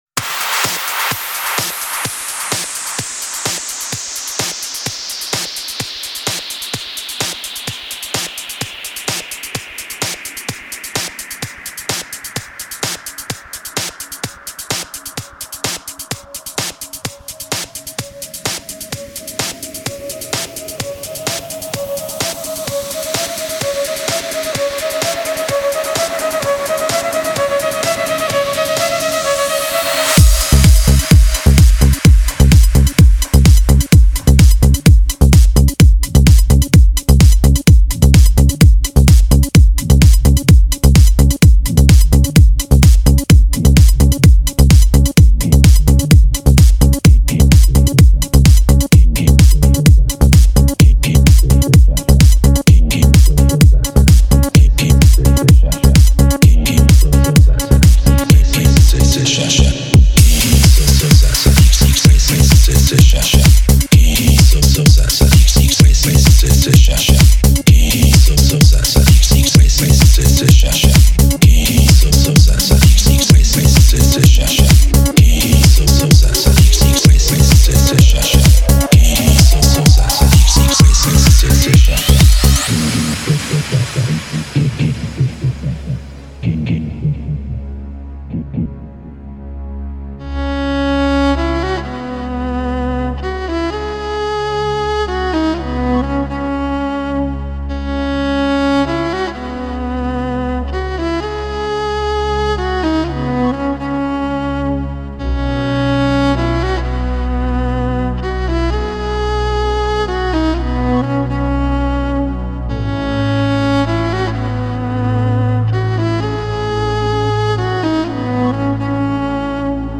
Жанр: House - Electro